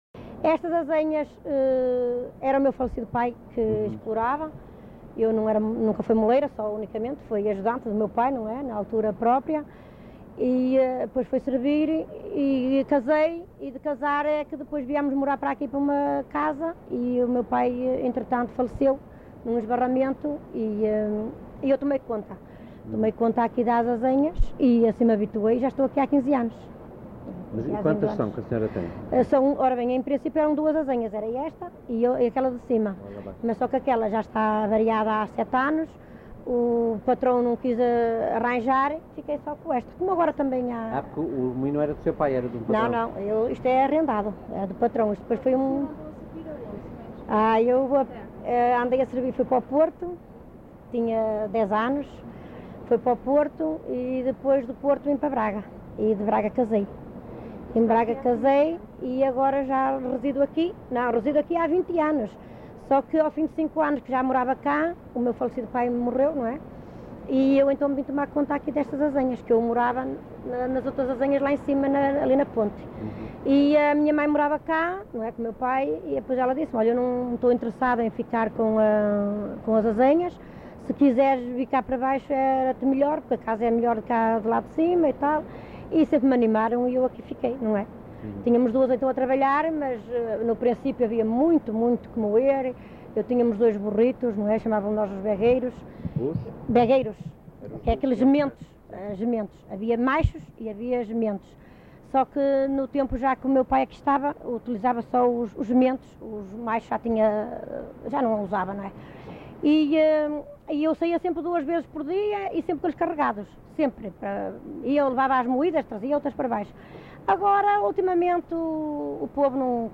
LocalidadeFiscal (Amares, Braga)